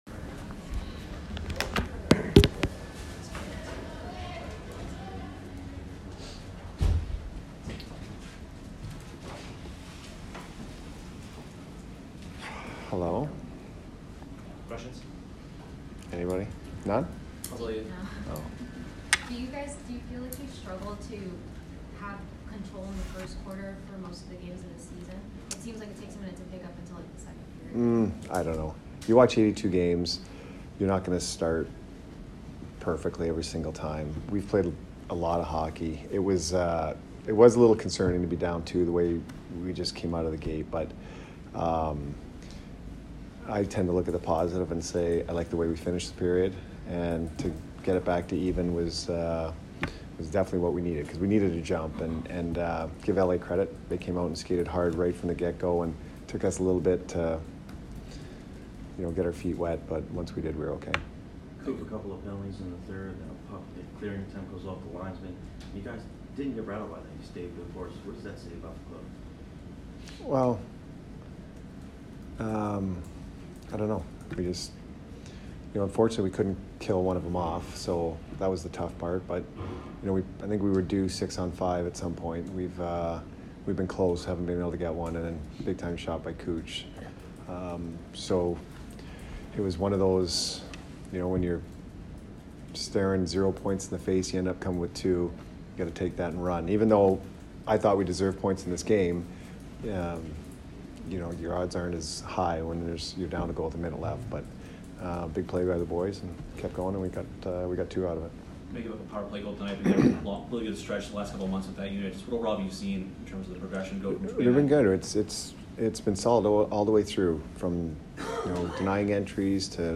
Jon Cooper post-game 1/14